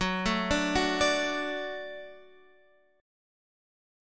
Gbm#5 chord